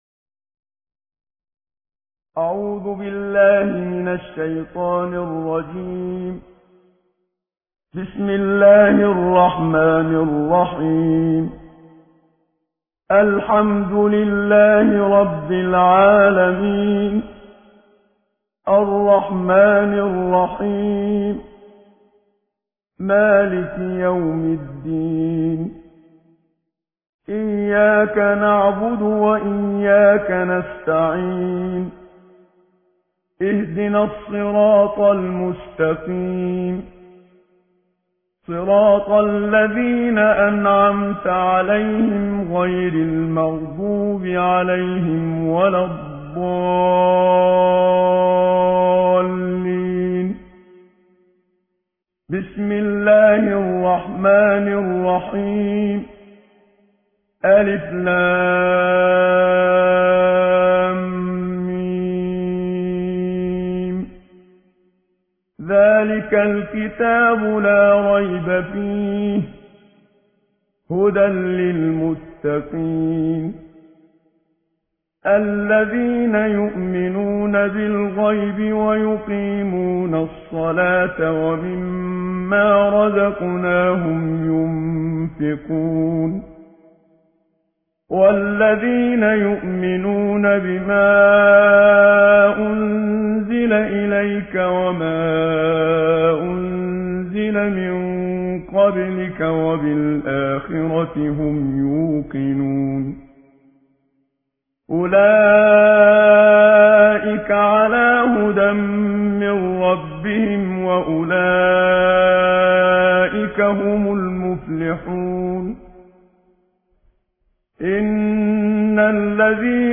صوت/ ترتیل جزء اول قرآن توسط استاد منشاوی